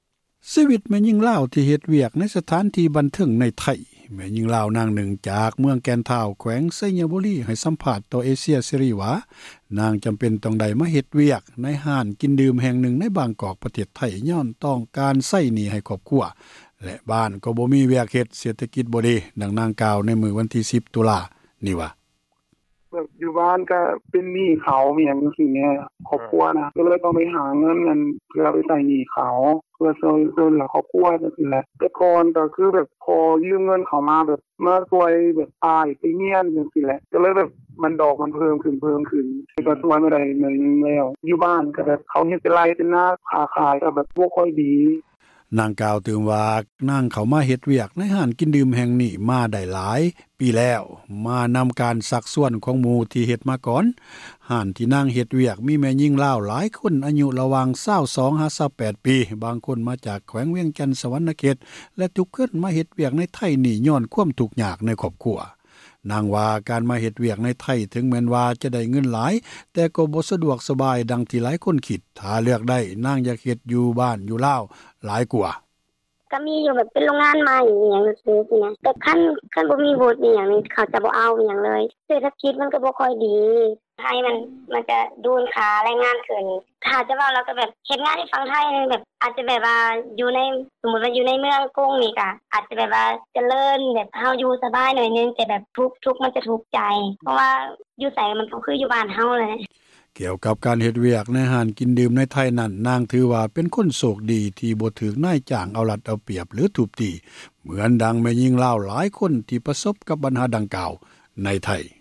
ແມ່ຍິງລາວນາງນຶ່ງ ຈາກເມືອງແກ່ນທ້າວ ແຂວງໄຊຍະບູຣີ ໃຫ້ສຳພາດ ຕໍ່ເອເຊັຽເສຣີວ່າ ນາງຈຳເປັນຕ້ອງໄດ້ໄປເຮັດວຽກ ໃນຮ້ານກິນດື່ມ ແຫ່ງນຶ່ງ ໃນບາງກອກປະເທດໄທ ຍ້ອນຕ້ອງການໃຊ້ຫນີ້ ໃຫ້ຄອບຄົວ ແລະຢູ່ບ້ານ ກໍບໍ່ມີວຽກເຮັດ ເສຖກິດກໍບໍ່ດີ.